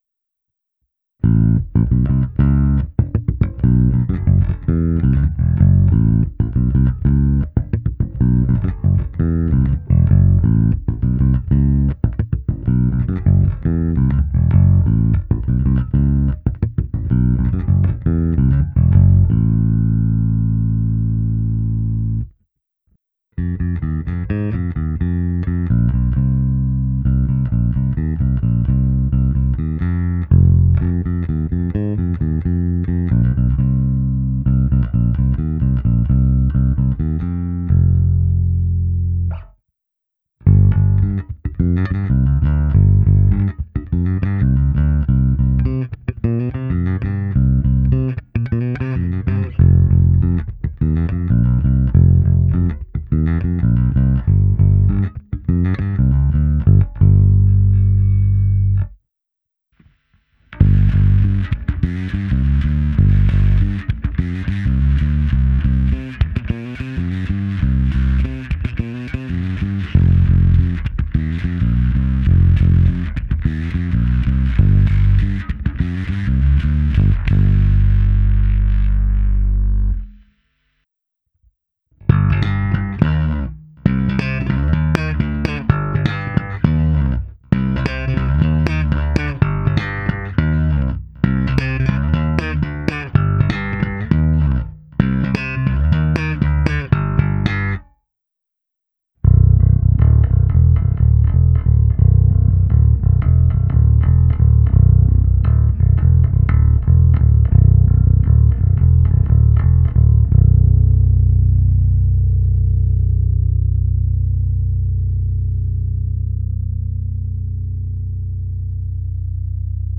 Nahrávka se simulací aparátu, kde bylo použito i zkreslení a hra slapem, na konci ještě ukázka na struně H.